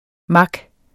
Udtale [ ˈmɑg ]